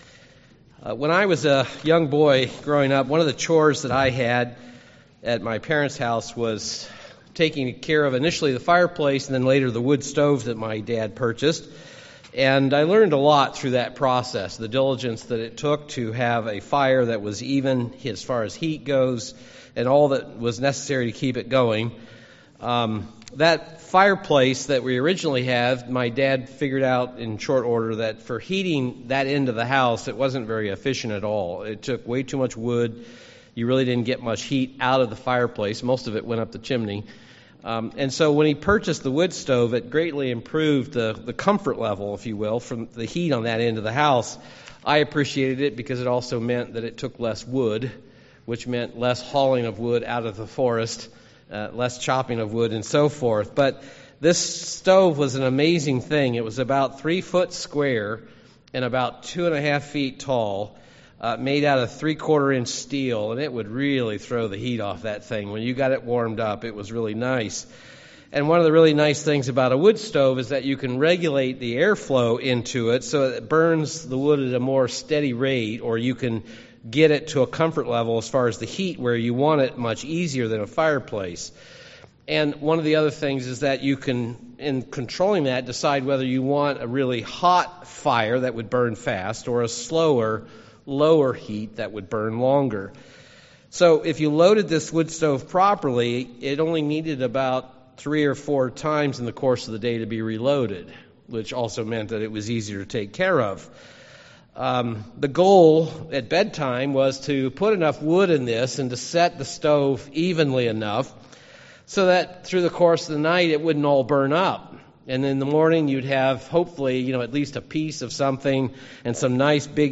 Given in Milwaukee, WI
Print You and I are to have a fire in us that we cannot neglect. fire stir up God’s Spirit Stirring up God's Spirit zeal zealous for the work UCG Sermon Studying the bible?